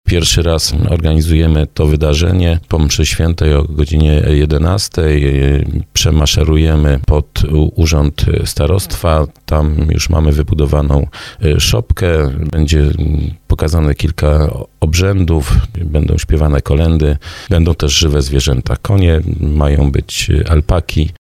– zapowiada starosta dąbrowski Lesław Wieczorek.